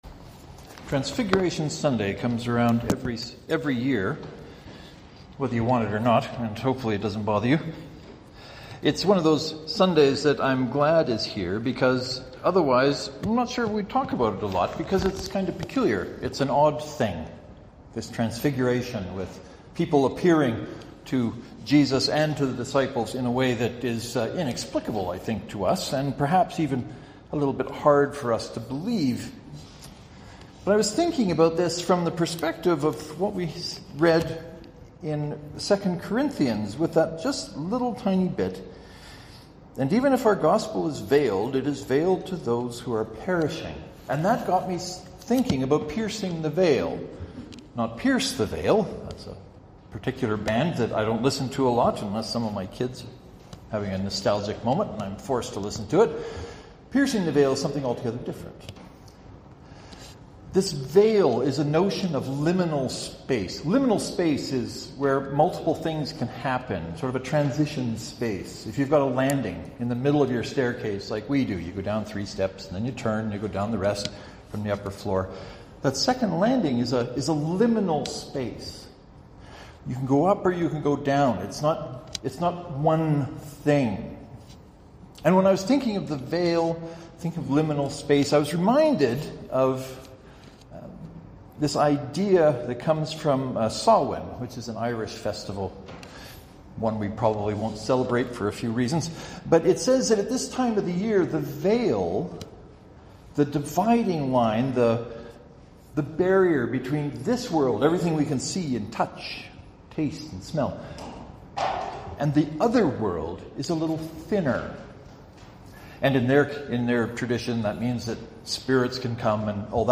Transfiguration Sunday is another Sunday which is celebrated, or at least noted every year.